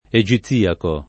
eJiZZ&ako] etn. stor.; pl. m. ‑ci — dell’antico Egitto; ma solo a proposito di santa Maria Egiziaca, dell’unguento egiziaco, dei giorni egiziaci, e in pochi altri casi — cfr. oziaco